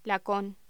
Locución: Lacón
voz